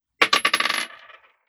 Bouncing Bullet 002.wav